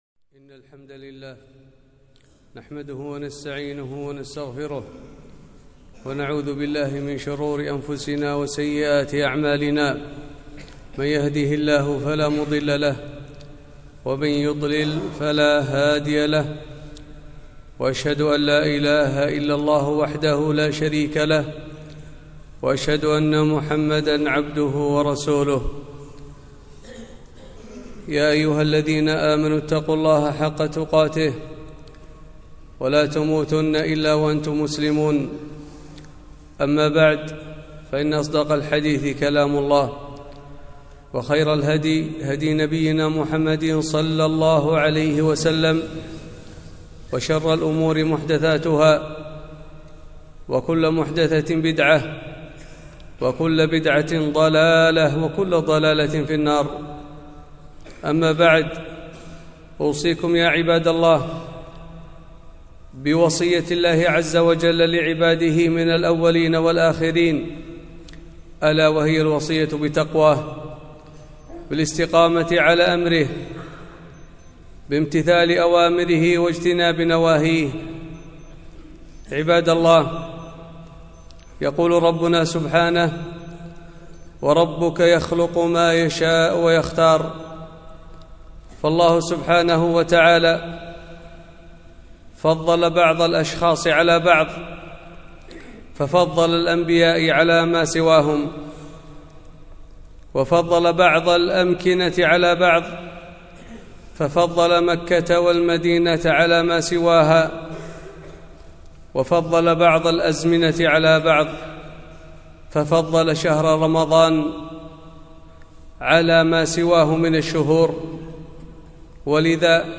خطبة - تدارك رمضان